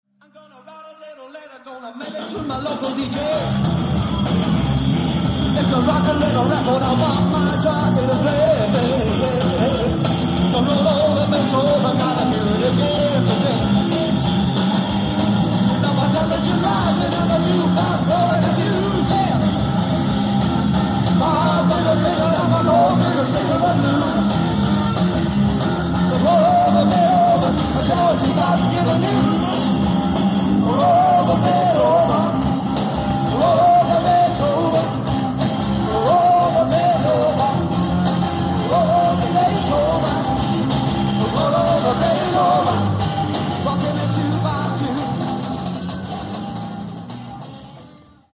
Sound:  Remastered
Source:  Audience